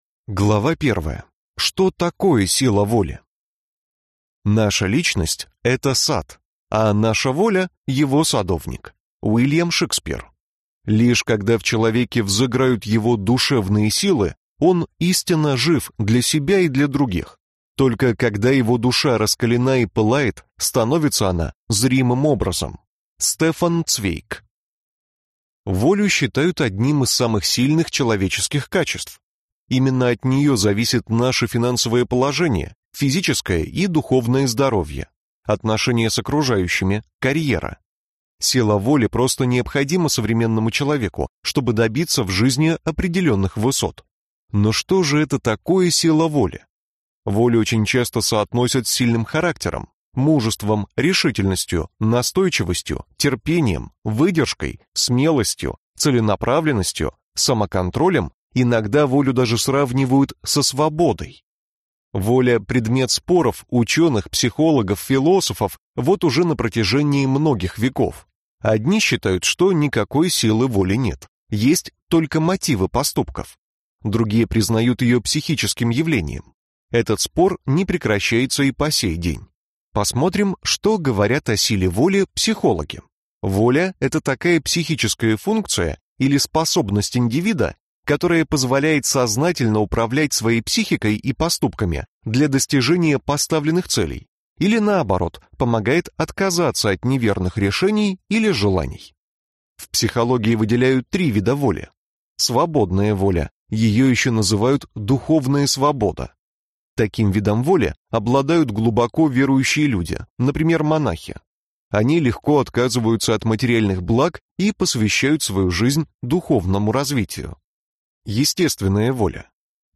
Аудиокнига Сила воли. Секретные методики спецслужб | Библиотека аудиокниг
Прослушать и бесплатно скачать фрагмент аудиокниги